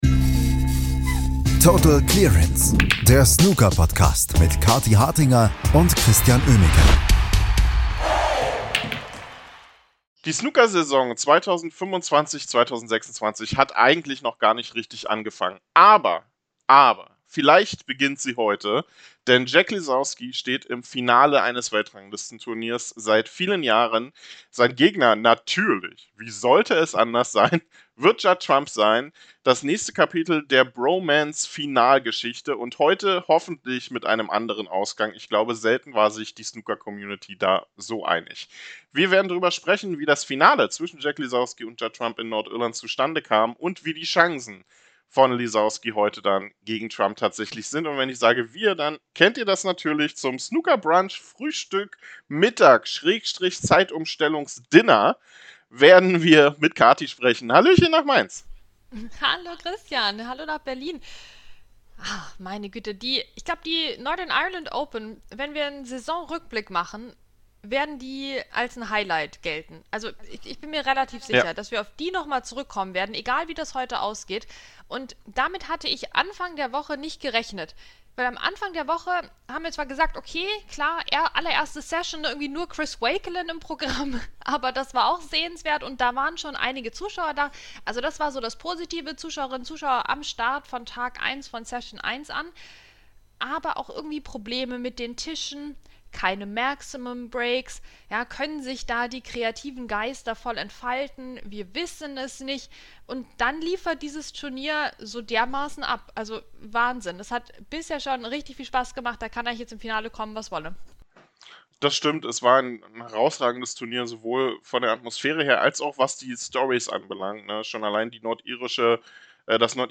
Ronnie O’Sullivan im Interview
Am Montag beginnen im englischen Crawley die English Open, das Auftaktturnier der Home Nation Series. Wir hatten Ronnie O'Sullivan im Interview.